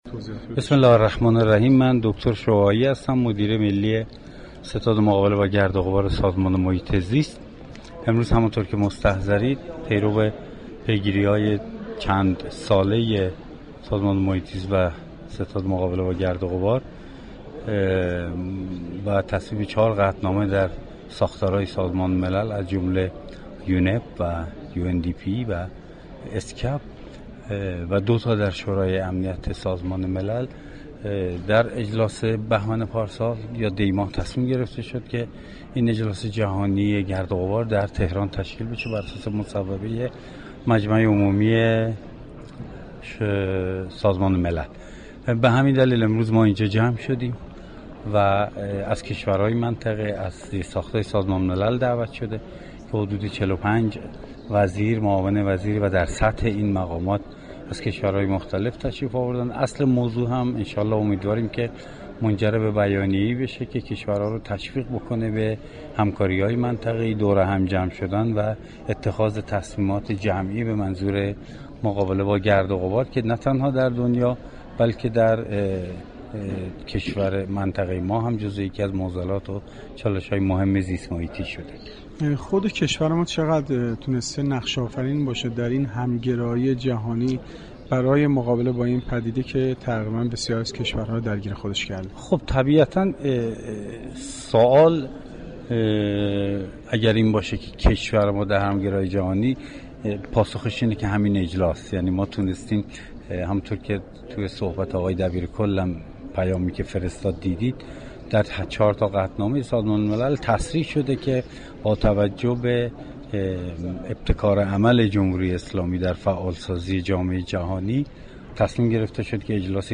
آقای دكتر شعاعی مدیر ملی ستاد مبارز ه با گرد و غبار سازمان حفاظت محیط زیست در گفتگوی اختصاصی با گزارشكر رادیو فرهنگ درباره ی این اجلاس گفت : در این اجلاس (برخی)از مقامات كشورهای منطقه حضور دارند و امیدواریم اصل موضوع این اجلاس منجر به بیانیه ای شود كه كشورها را به همكاری های منطقه ای و اتخاذ تصمیمات جدی به منظور مبارزه و مقابله با گرد و غبار كه یكی از چالشهای زیست محیطی است ، تشویق كند .